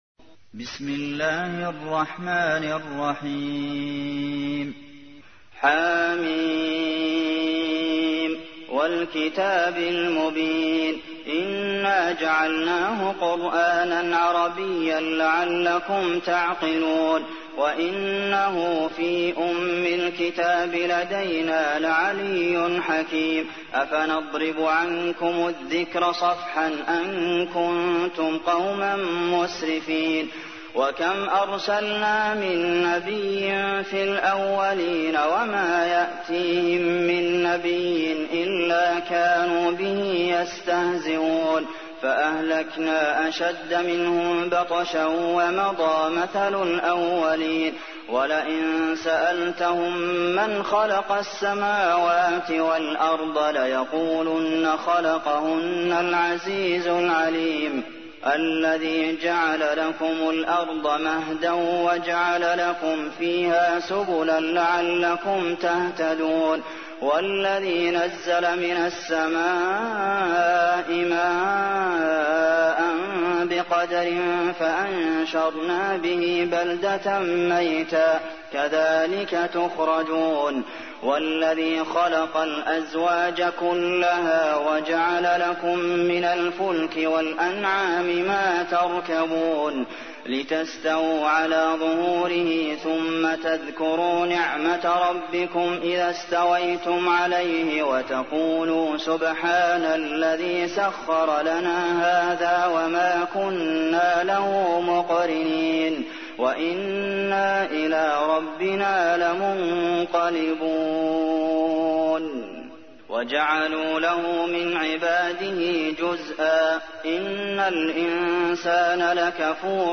تحميل : 43. سورة الزخرف / القارئ عبد المحسن قاسم / القرآن الكريم / موقع يا حسين